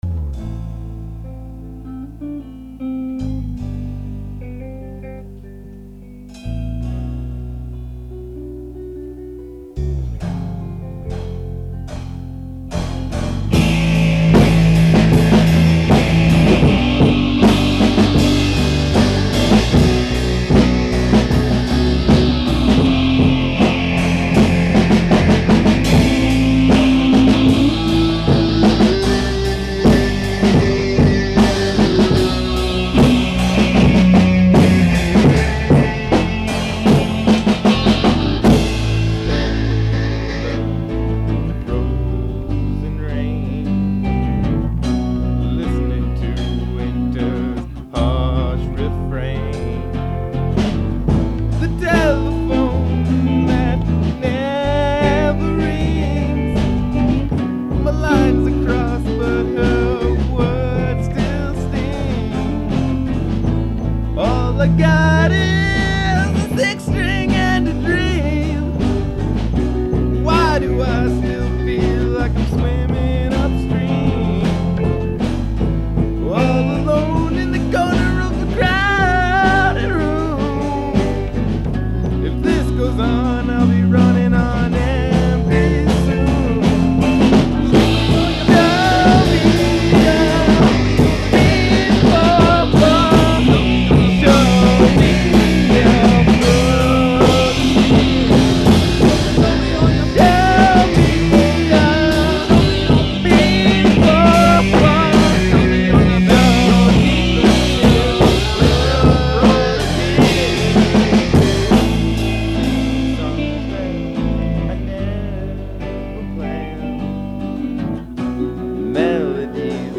our masterpiece. just listen to the part when the trombone comes in!